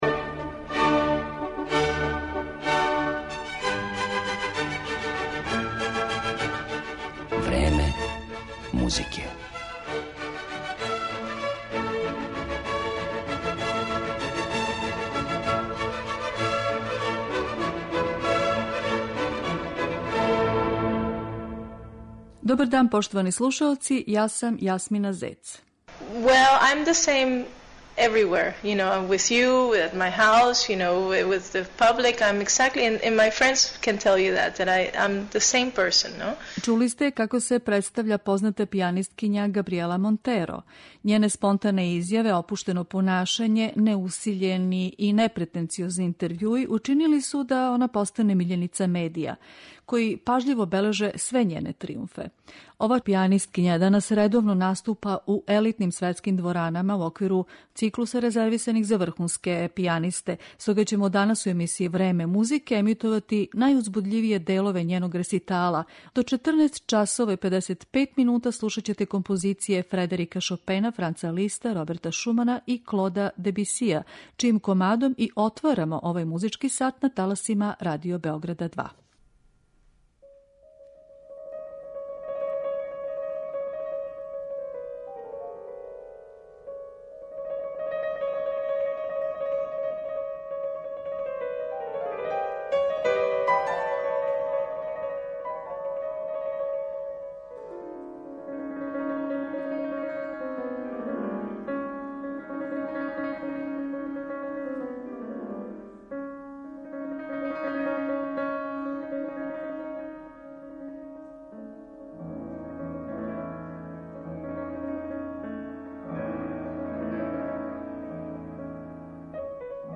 Емисију 'Време музике' посвећујемо познатој пијанисткињи из Венецуеле Габријели Монтеро, коју је имала задовољство да слуша и београдска публика.